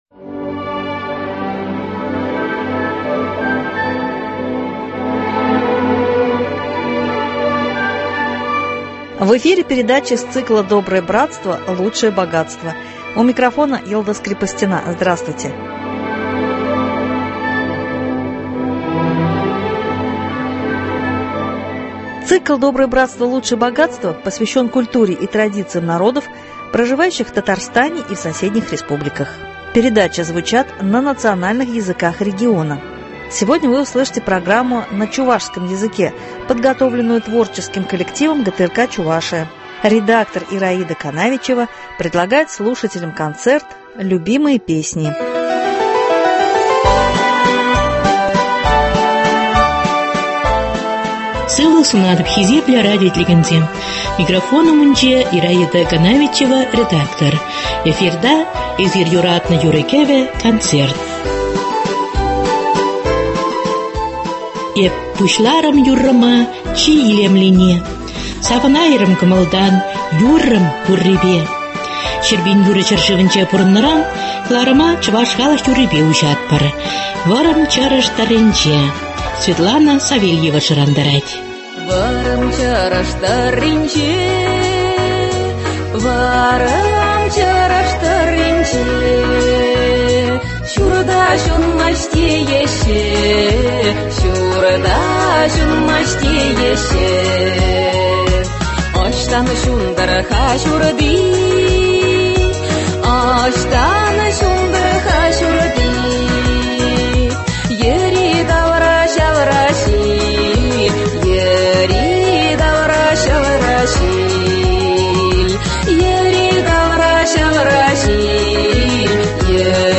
концерт «Любимые песни»